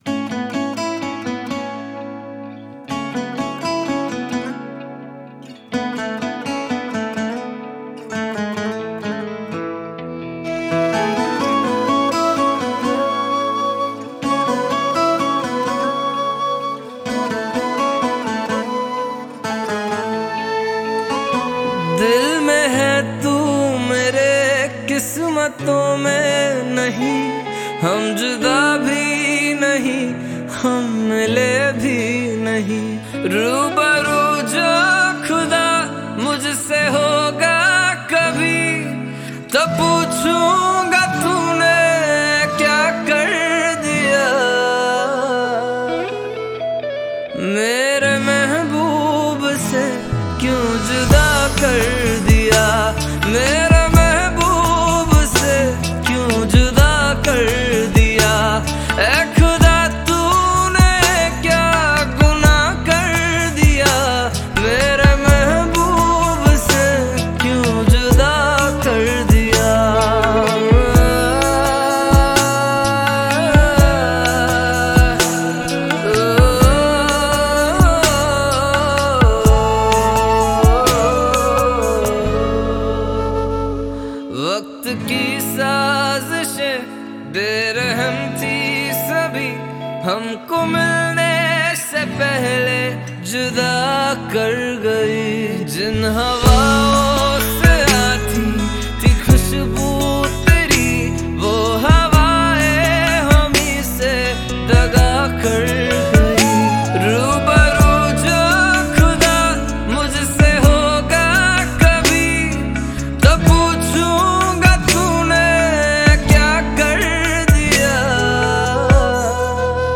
2020 Pop Mp3 Songs